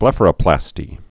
(blĕfər-ə-plăstē)